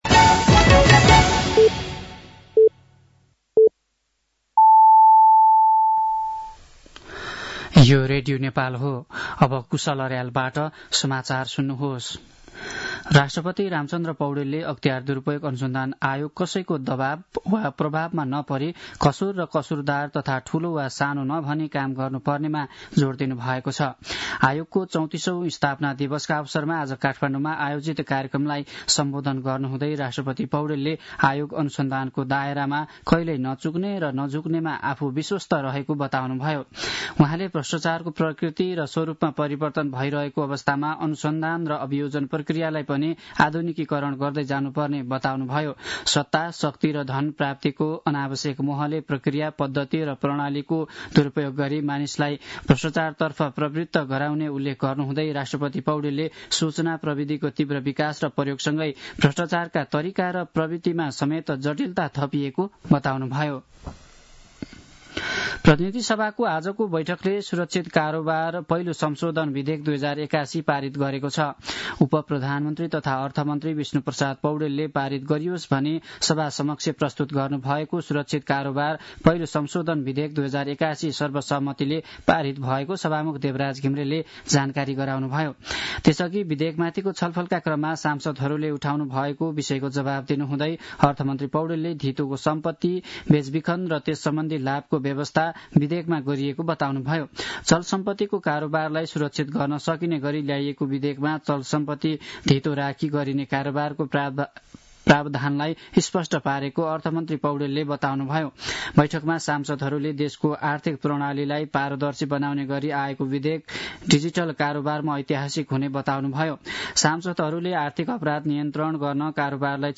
साँझ ५ बजेको नेपाली समाचार : २९ माघ , २०८१
5-pm-nepali-news-10-28.mp3